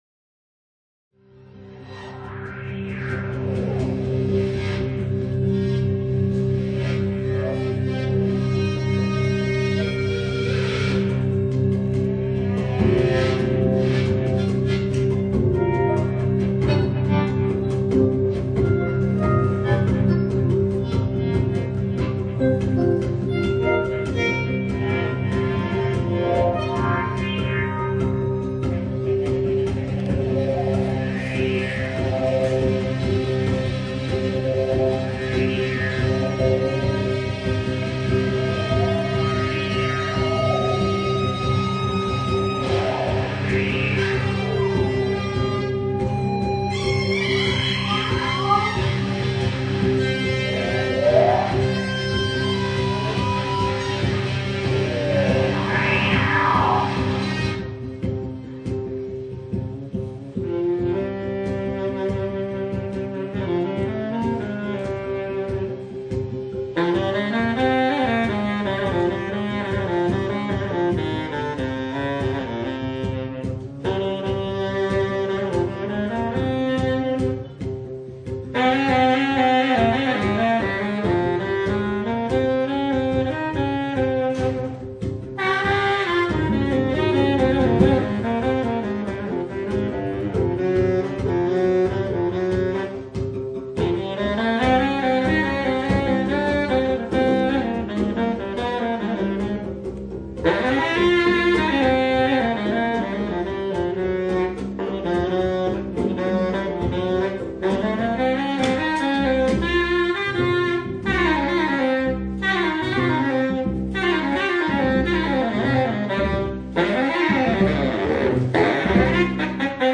Recorded live on April 2002 all around USA